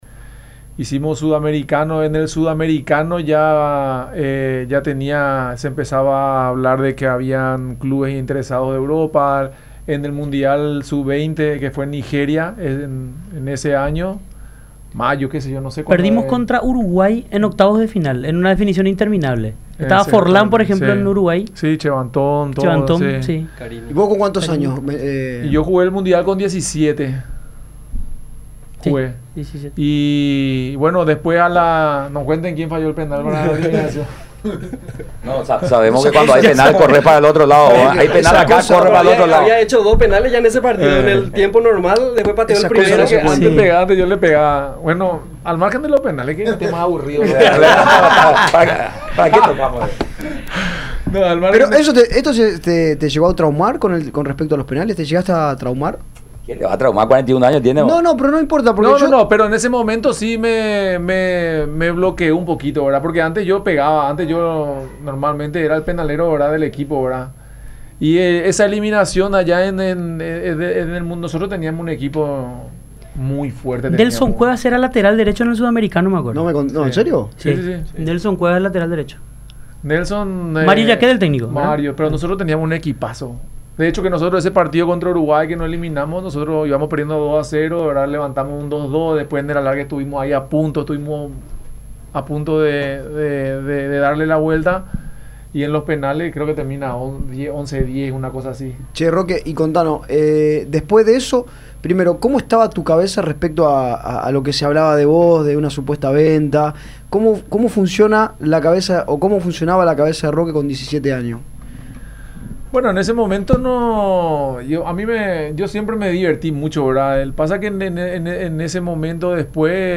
Roque Santa Cruz recordó cuando Emmanuel Adebayor vino a Paraguay para jugar por Olimpia. Todos los integrantes del plantel trataban de 'sacarle temas', rememoró entre risas.